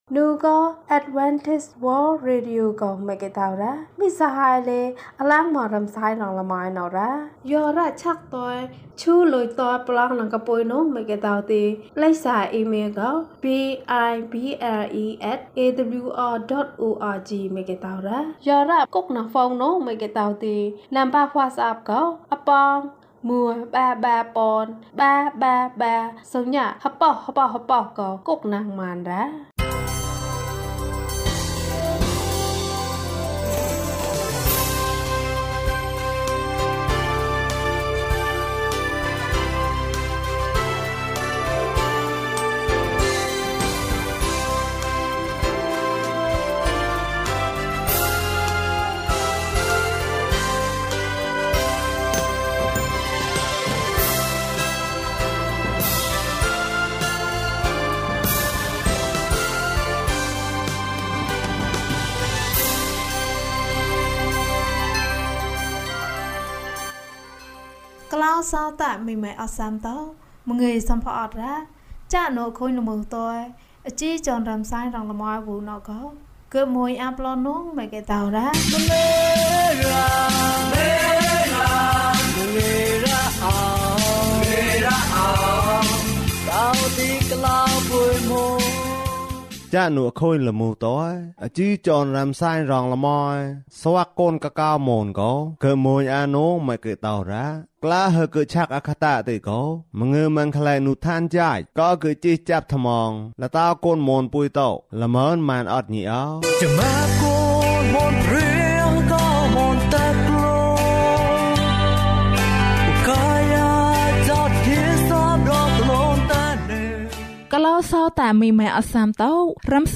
ခရစ်တော်ထံသို့ ခြေလှမ်း။၃၇ ကျန်းမာခြင်းအကြောင်းအရာ။ ဓမ္မသီချင်း။ တရားဒေသနာ။